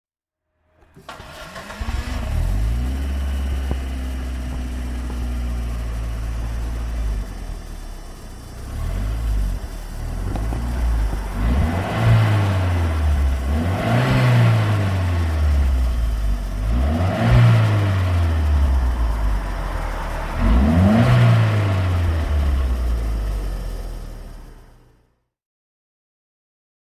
Ford Sierra Cosworth 4x4 (1991) - Starten und Leerlauf